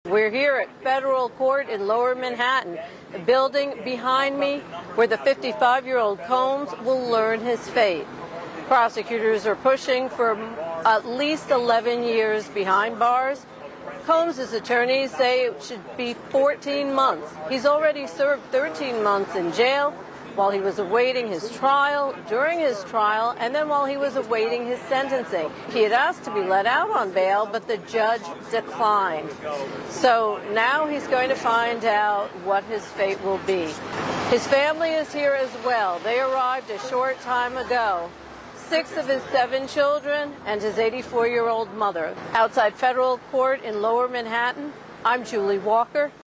reports on sentencing day for Sean "Diddy" Combs.